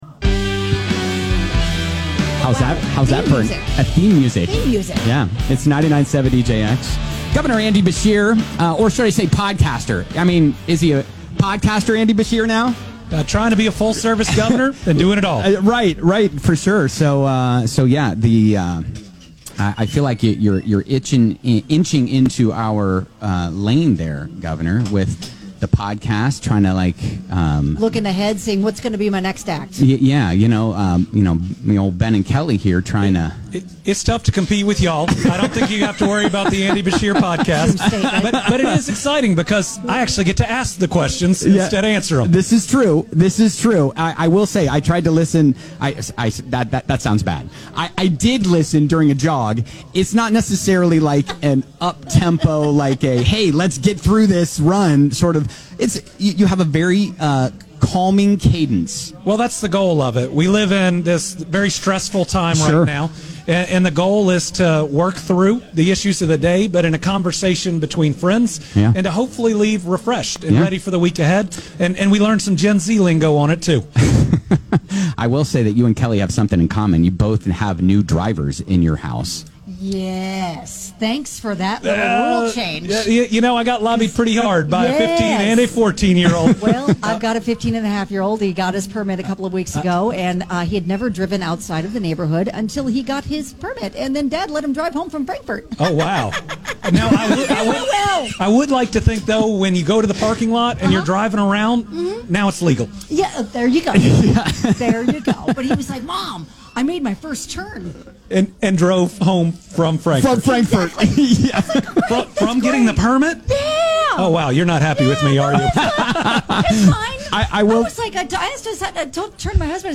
Governor Andy Beshear stopped by the show.